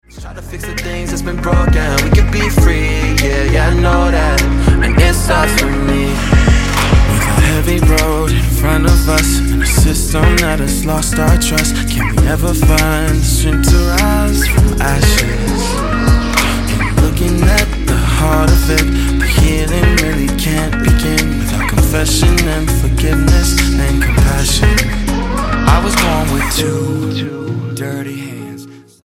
STYLE: Pop
distorted vocals